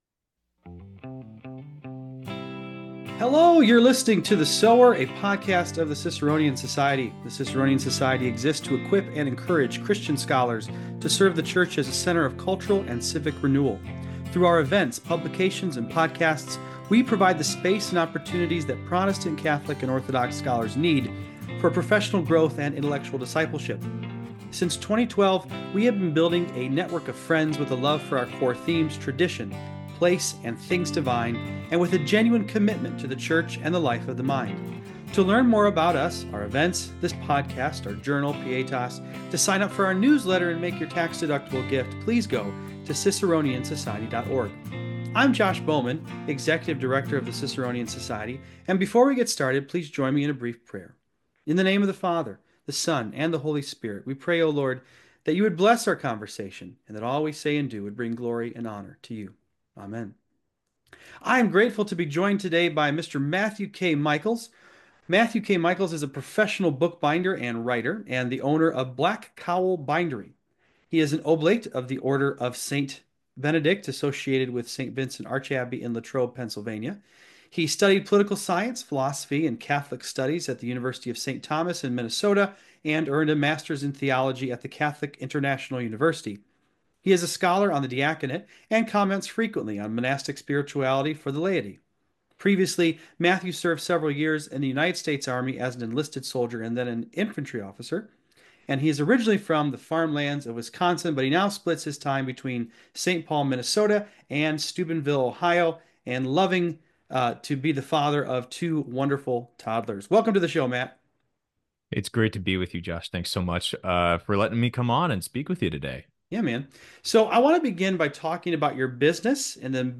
In addition to talking about the craft of bookbinding, we also discuss the idea of human scale, running a small business, common arts education, the trades, vocation, virtue, localism, Westerns, beer, and more! It's also painfully obvious that you're listening to two Midwesterners.